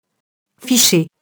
ficher [fiʃe]